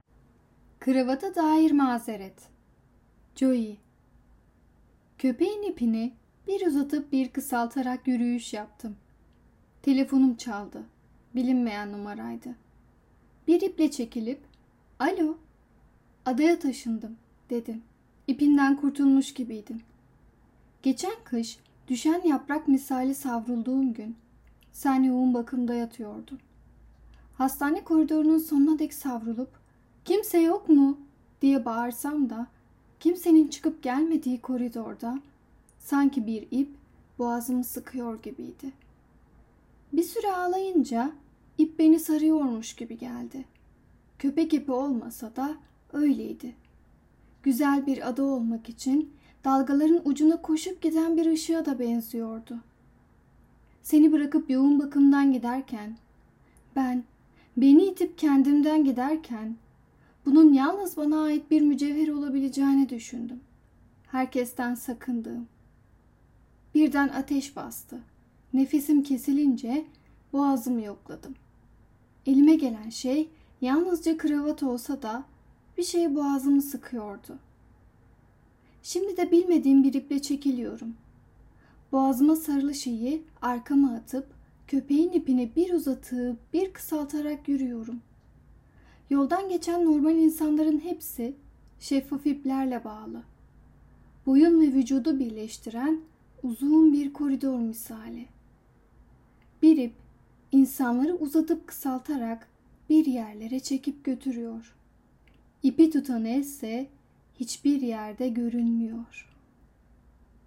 낭송하다!!